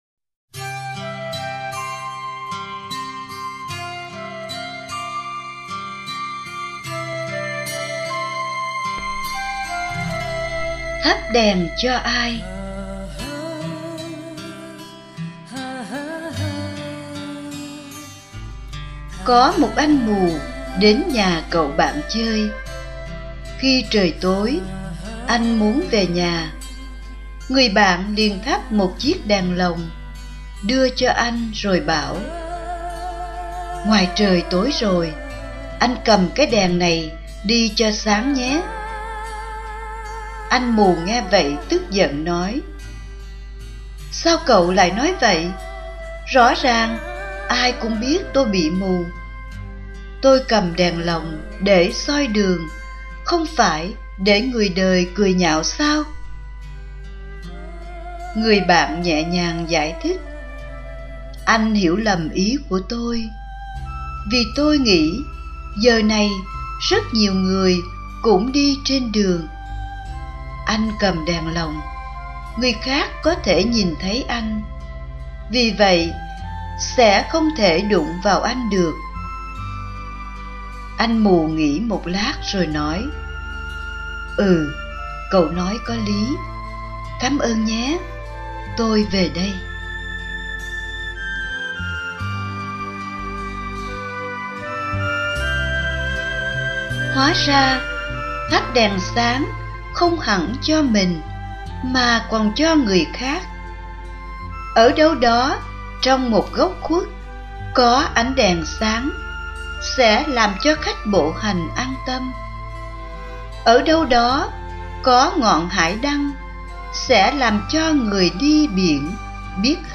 (Suy niệm Lễ Hiển Linh)